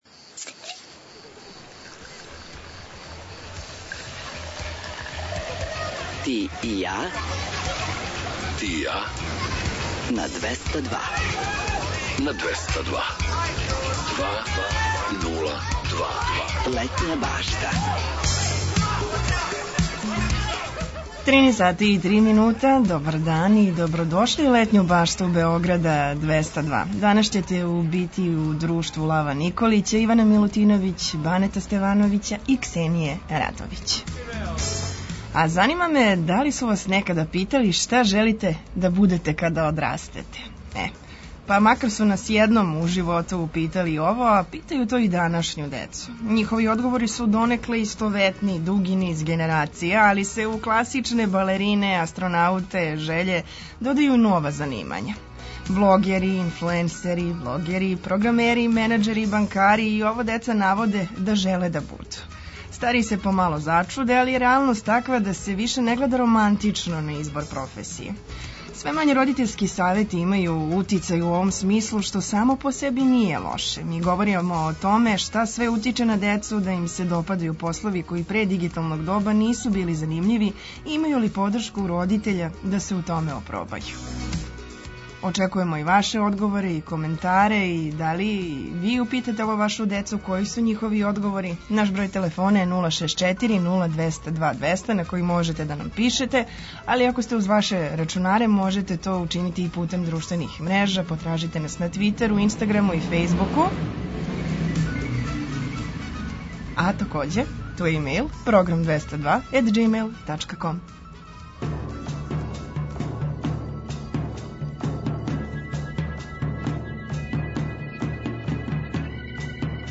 Обрадоваће вас и пола сата „домаћица”, песама из Србије и региона. Предлажемо вам предстојеће догађаје широм Србије, свирке и концерте, пратимо сервисне информације важне за организовање дана, а наш репортер је на градским улицама, са актуелним причама.